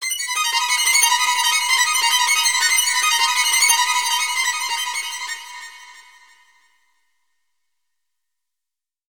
【効果音】バイオリンの怖いホラー音 - ポケットサウンド - フリー効果音素材・BGMダウンロード